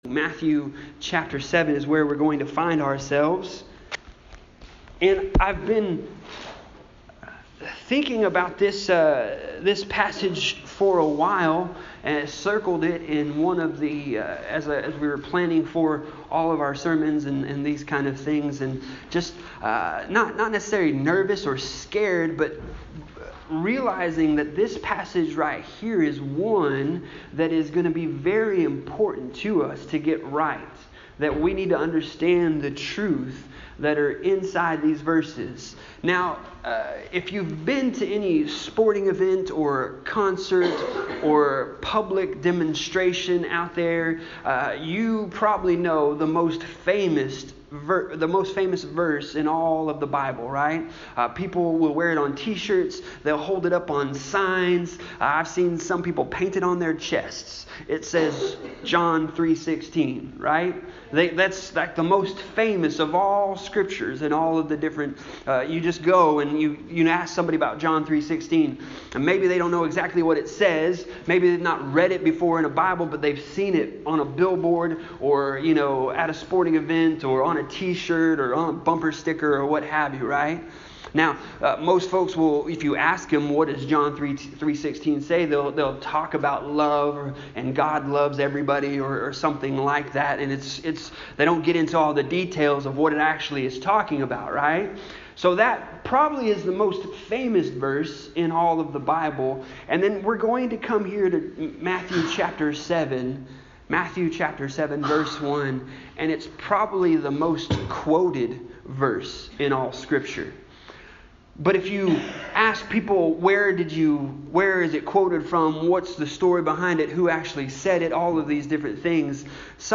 Passage: Matthew 7:1-12 Service Type: Sunday Morning